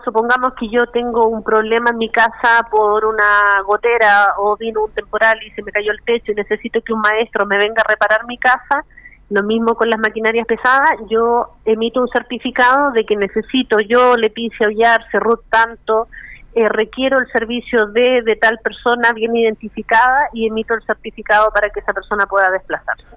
En conversación con Radio Bío Bío, la gobernadora de Llanquihue, Leticia Oyarce respondió consultas y dudas asociadas a los requerimientos adicionales que se van a exigir para el desplazamiento de trabajadores desde el lunes.
Lo explica la Gobernadora.